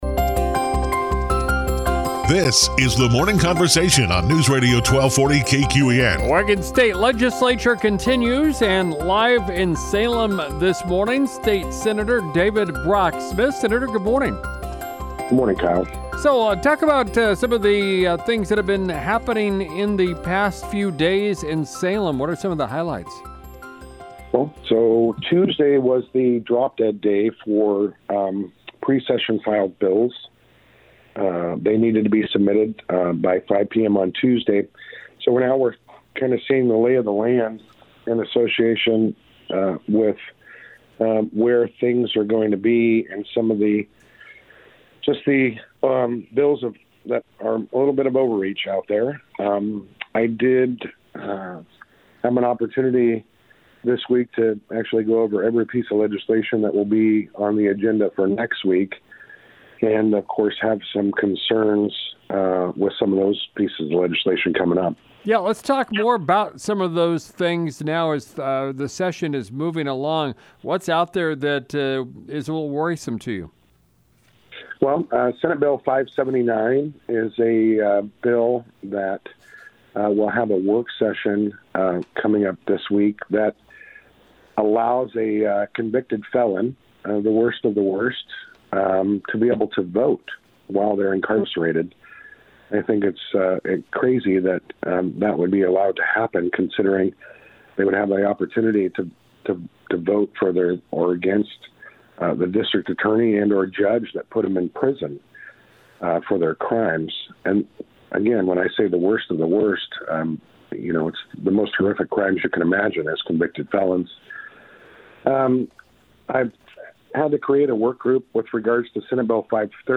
Live from Salem, a legislative update from State Senator David Brock Smith.